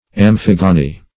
Amphigony \Am*phig"o*ny\, n.